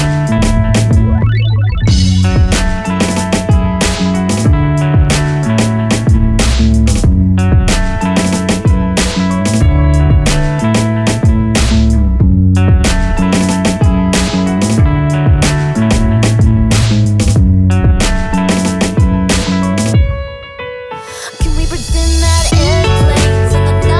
no Backing Vocals Pop
Pop (2000s)